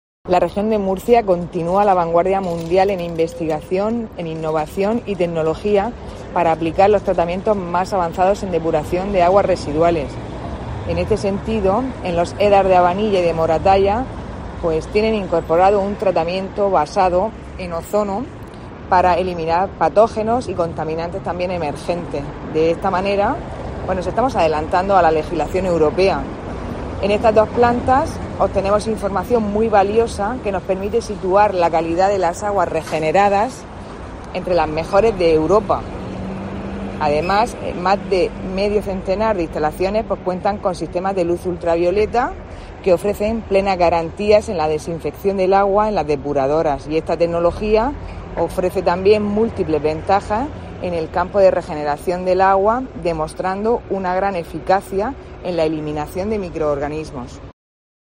Sara Rubira, consejera de Agua, Agricultura, Ganadería y Pesca